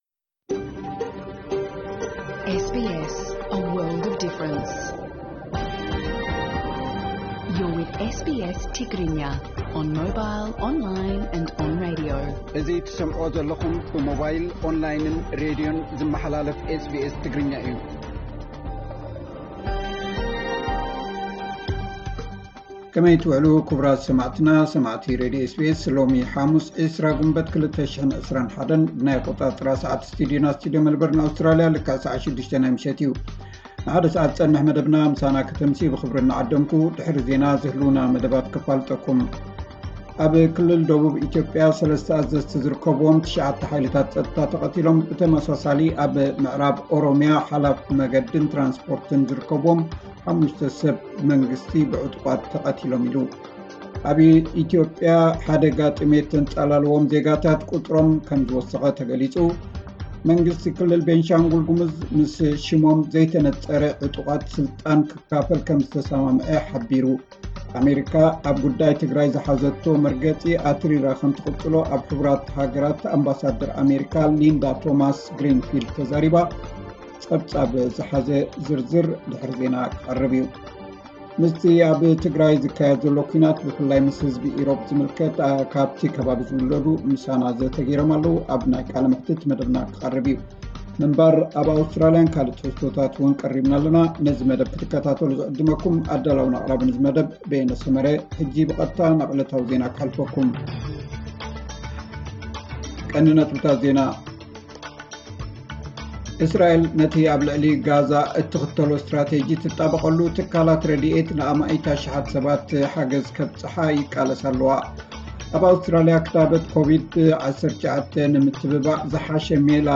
ዕለታዊ ዜና 20 ግንቦት 2021 SBS ትግርኛ